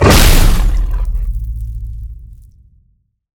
giant_stomp_0.ogg